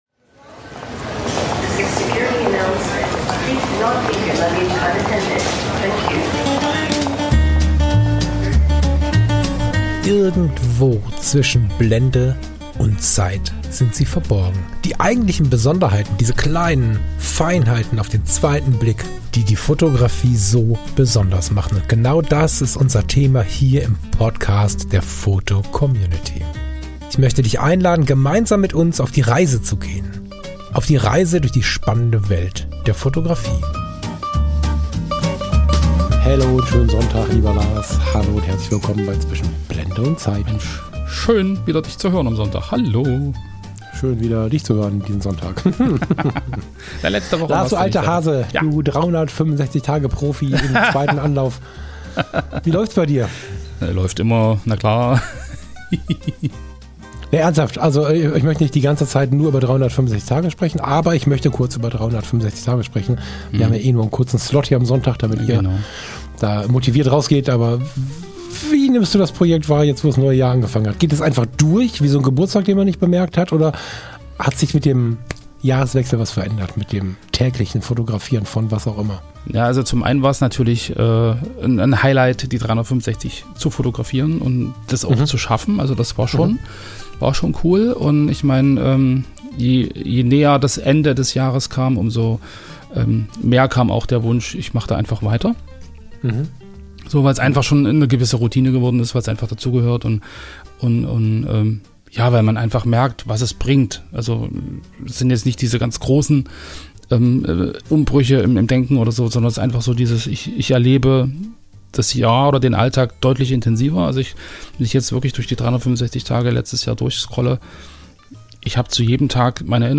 In dieser Sonntagsfolge sitzen wir zusammen und sprechen über das 365-Tage-Projekt – nicht als Vorsatz, sondern als Teil des Alltags. Wir reden darüber, wie sich das tägliche Fotografieren anfühlt, wenn aus Druck eine ruhige Routine wird, wie Motive plötzlich von selbst auftauchen und warum es oft genau die stressigsten Tage sind, an denen ein Foto am meisten hilft.
Eine leise Folge über Fotografie als Auszeit – und darüber, wie ein einziges Bild helfen kann, wieder bei sich anzukommen.